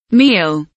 meal kelimesinin anlamı, resimli anlatımı ve sesli okunuşu